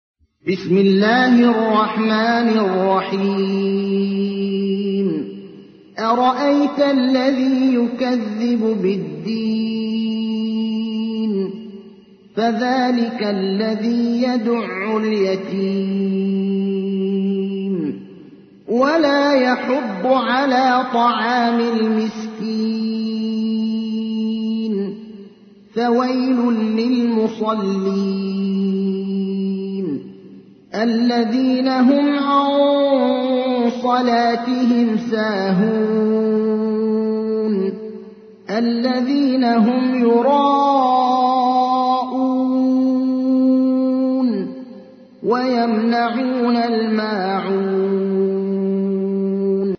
تحميل : 107. سورة الماعون / القارئ ابراهيم الأخضر / القرآن الكريم / موقع يا حسين